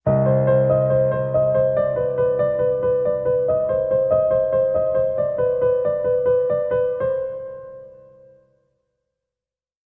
Music Logo; Fast Horror Piano Melody.